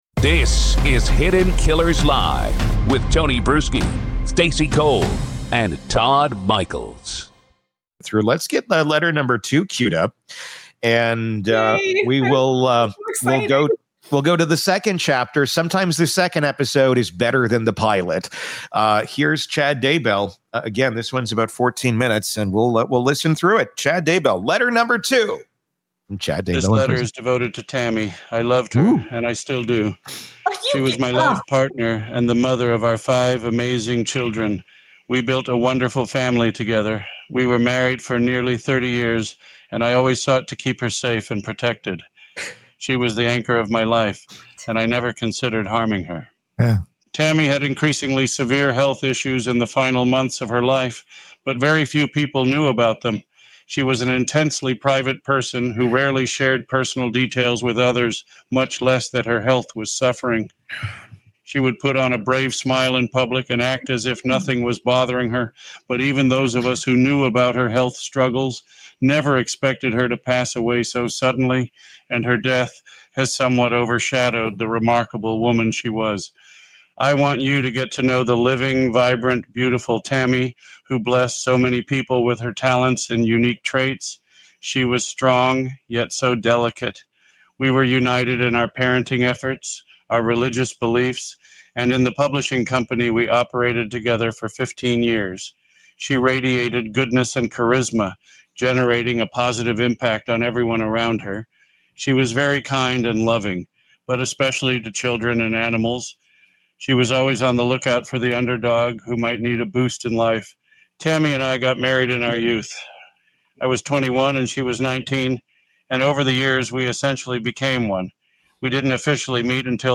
The hosts break down the surreal contradictions. How does a man convicted of family annihilation manage to write love letters that sound like Hallmark scripts?
With sharp banter and biting analysis, this segment reveals the psychological gymnastics of a killer desperate to rewrite his own history.